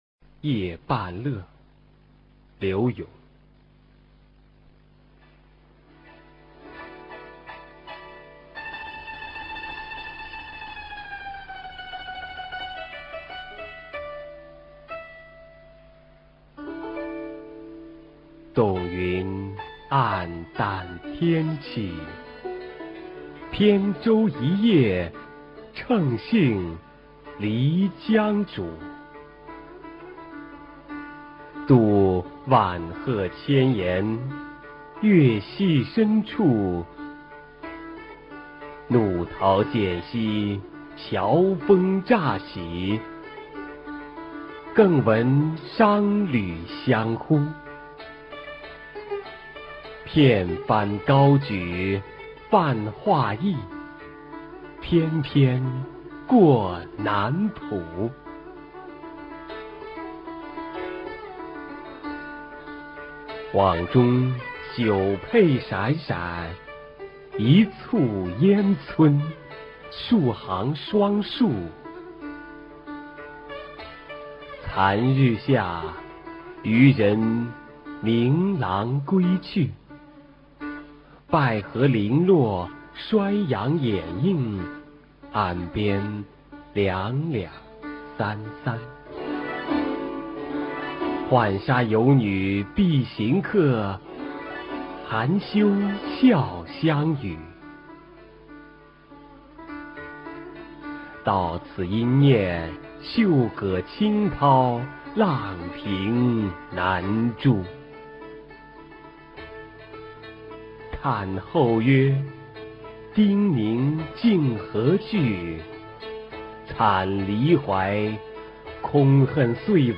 [宋代诗词朗诵]柳永-夜半乐a 古诗词诵读